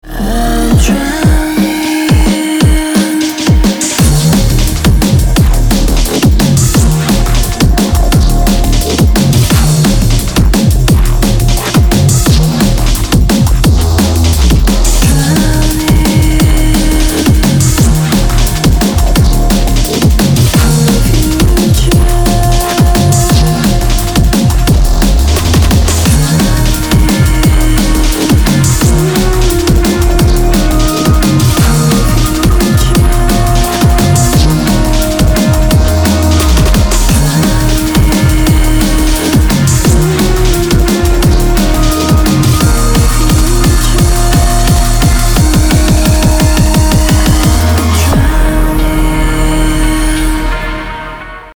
• Качество: 320, Stereo
громкие
мощные
drum n bass
drum&bass
DnB
neurofunk